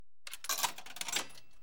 15. Звук забрасывания монетки в игровой автомат